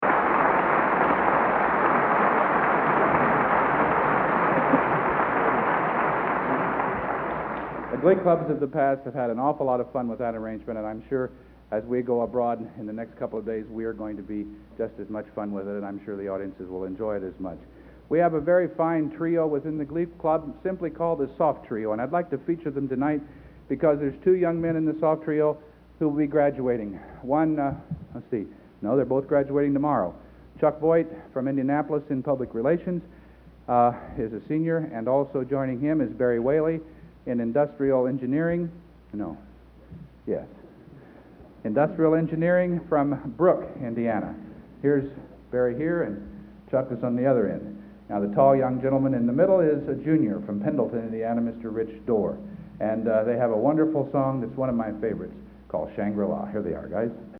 Location: West Lafayette, Indiana
Genre: | Type: Director intros, emceeing |End of Season